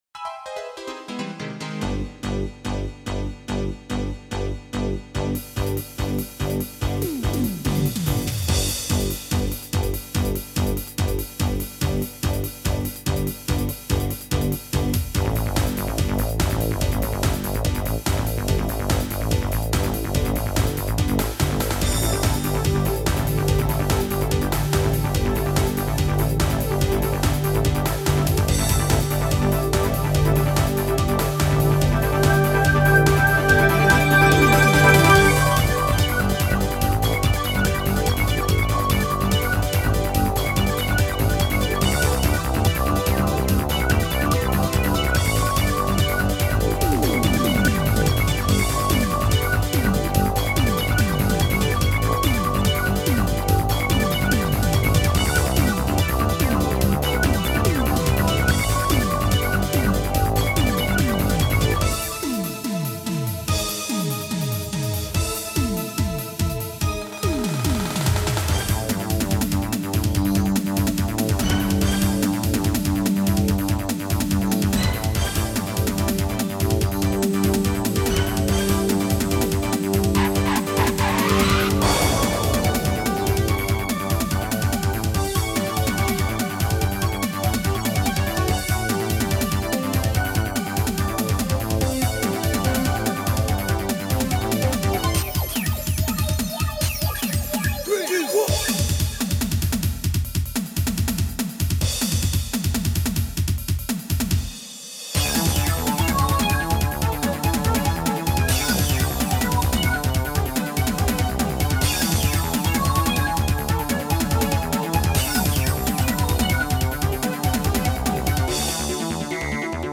Recorded from Roland SC-8850